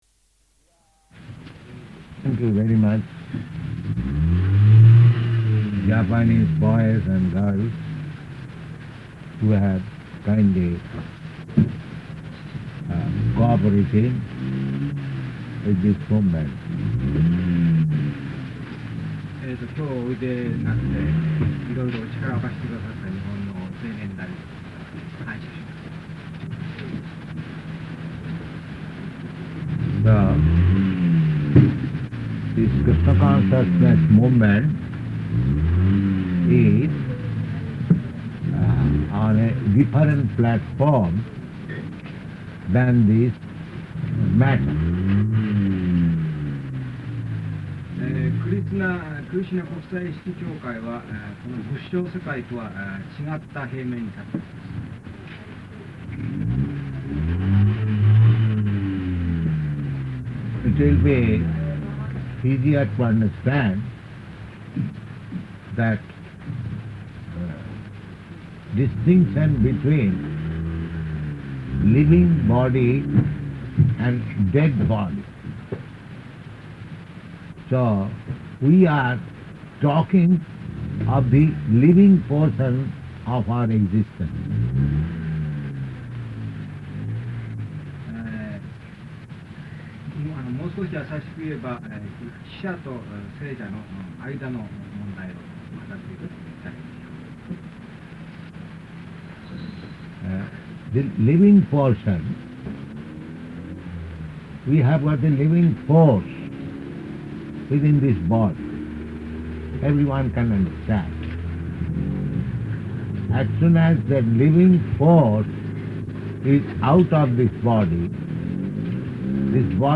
Lecture
Type: Lectures and Addresses
Location: Tokyo
[translated into Japanese throughout]